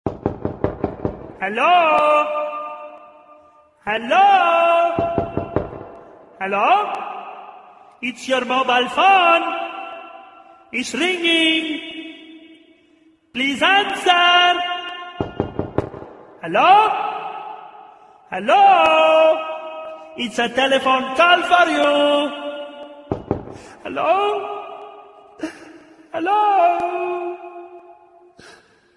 SMS Alert
Notification Sms Alert Alert Fun Phone Ring Knock